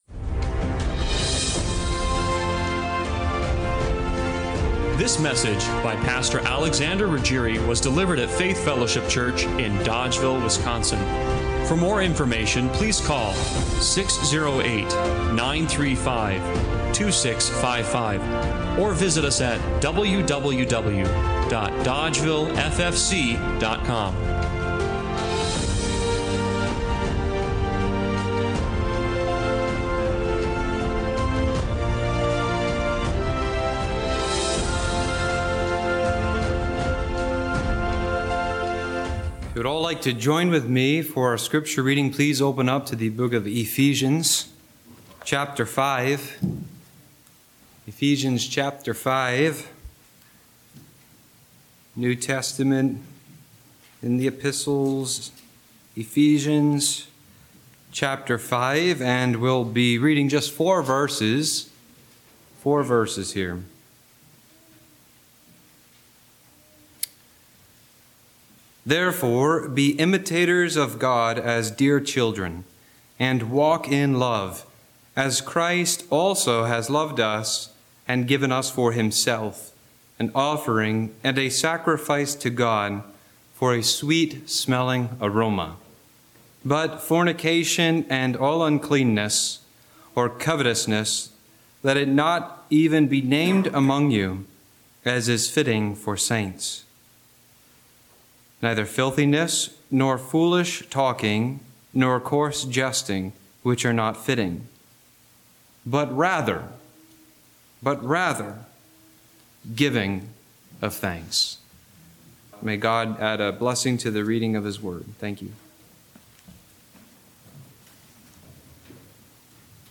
Ephesians 5:1-4 Service Type: Sunday Morning Worship What if the biggest obstacle to gratitude…is your natural bent away from it?